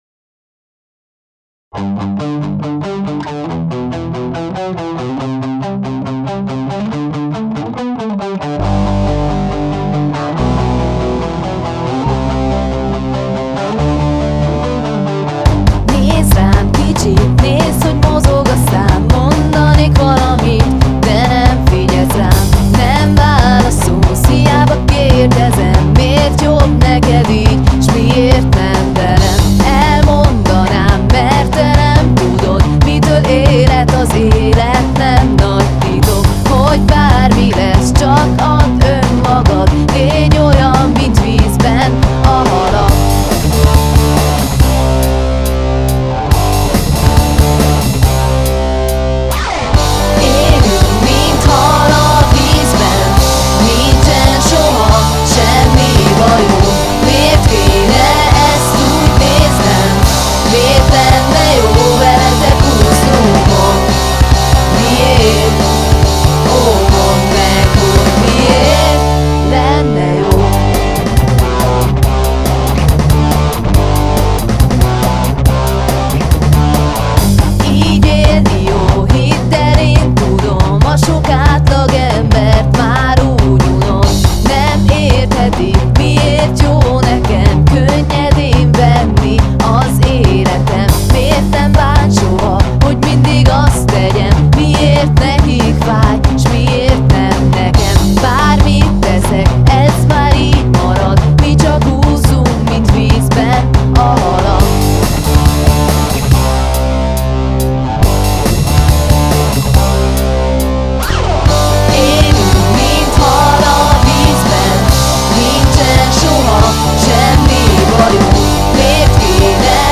gitár, ének
basszgitár